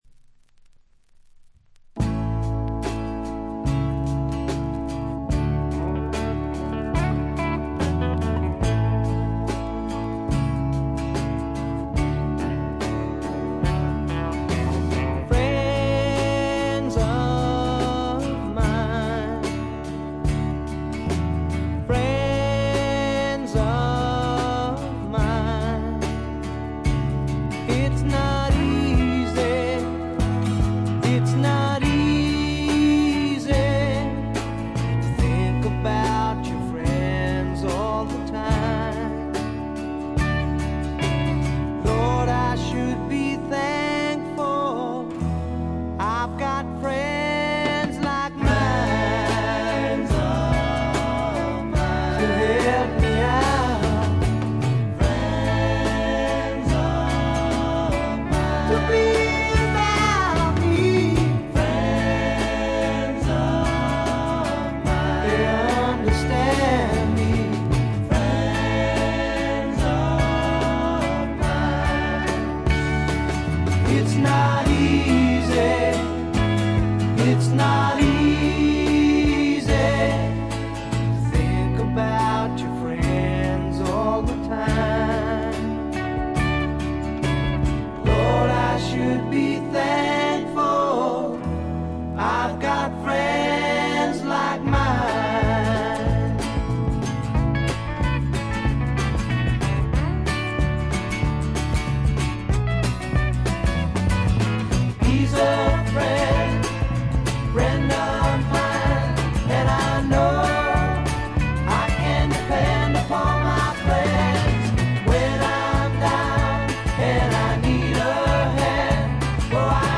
渋い英国産スワンプ、理想的な「イギリスのアメリカ」！